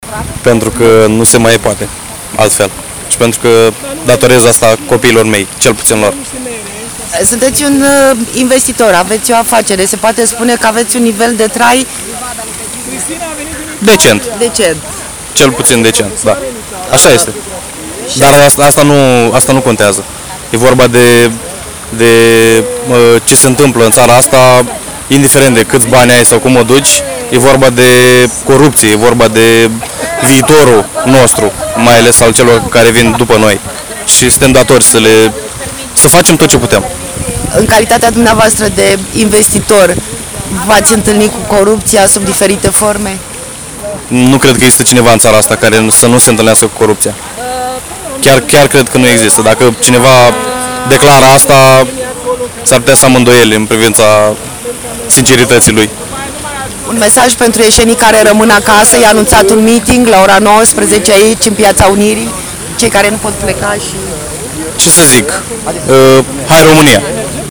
Un tînăr om de afaceri
10-august-investitor-tinar.mp3